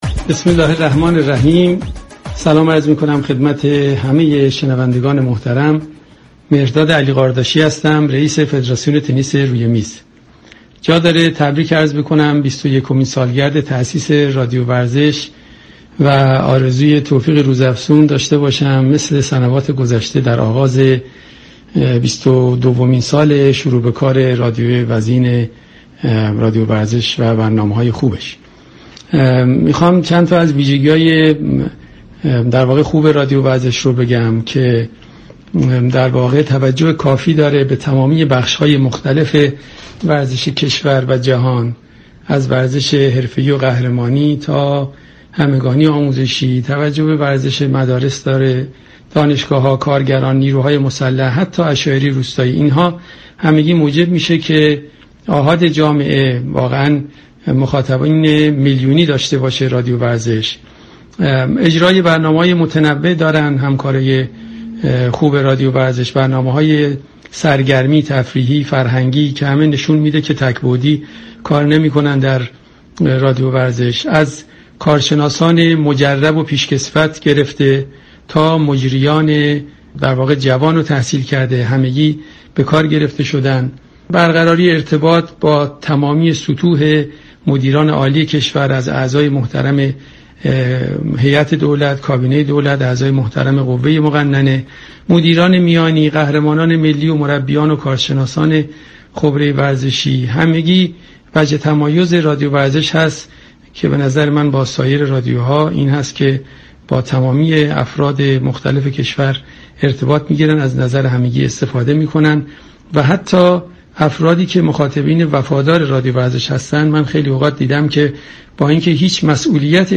ویژه برنامه "شكوه یك تولد" به مناسبت آغاز بیست و دومین سالگرد شبكه رادیویی ورزش یكشنبه (8 تیر) ساعت 7:10 تا 10 از شبكه رادیویی ورزش تقدیم شنوندگان شد.